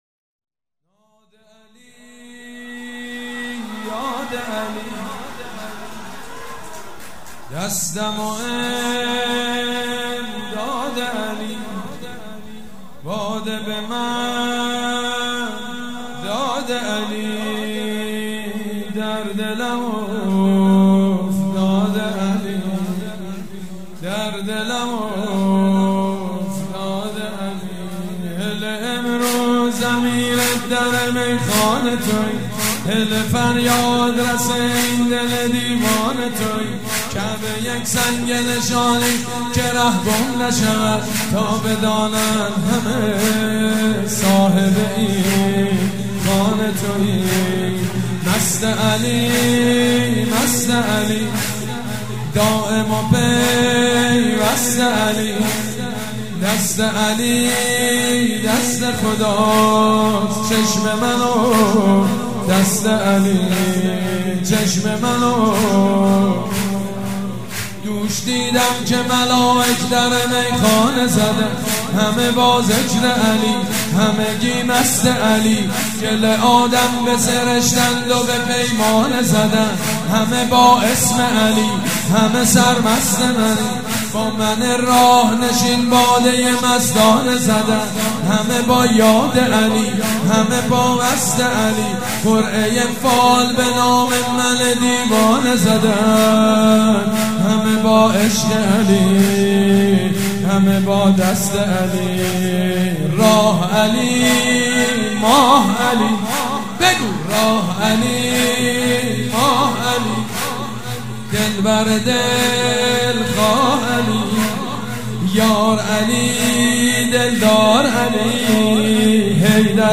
مراسم میلاد پیامبر خاتم(ص)و حضرت امام جعفر صادق(ع)
سرود
حاج سید مجید بنی فاطمه